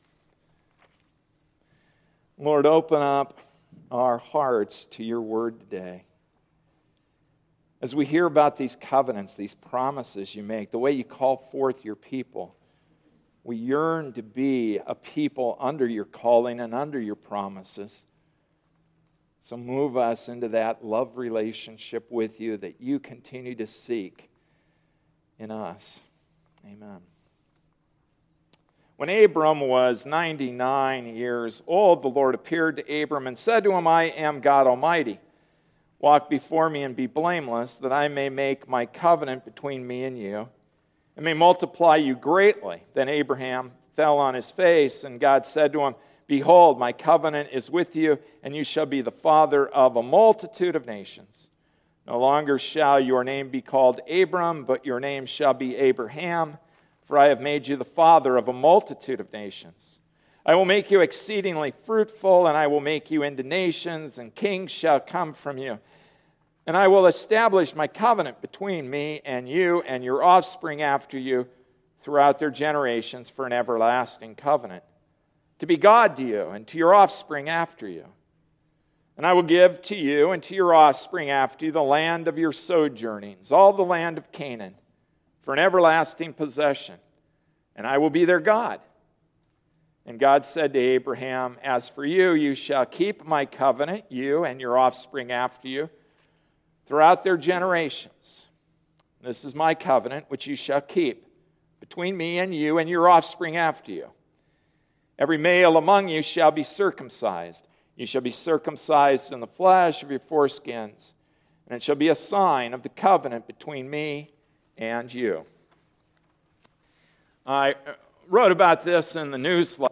Passage: Genesis 17:1-11 Service Type: Sunday Morning Service